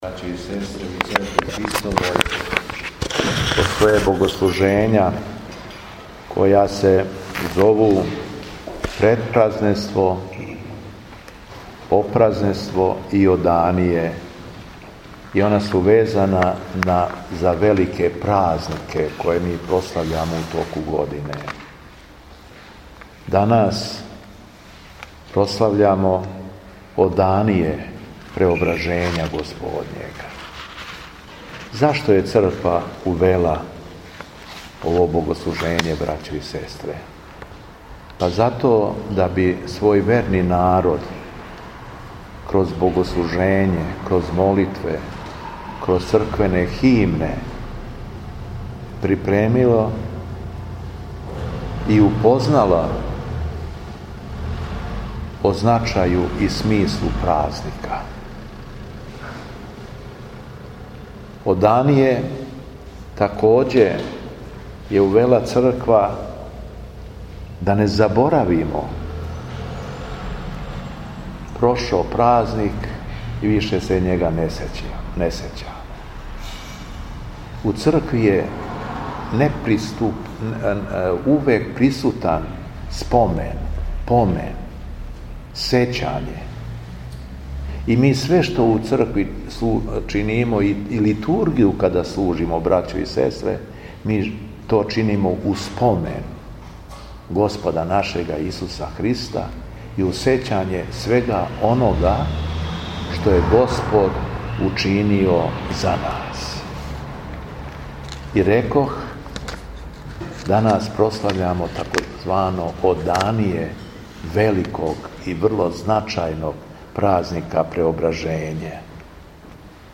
У уторак 26. августа 2025. године, Његово Високопресвештенство Митрополит шумадијски Г. Јован служио је Свету Литургију у храму Светог Пантелејмона у крагујевачком насељу Станово уз саслужење братства овога светога храма.
Беседа Његовог Високопреосвештенства Митрополита шумадијског г. Јована
Беседом се верном народу обратио Високопреосвећени Митрополит Јован, рекавши: